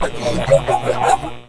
drown.wav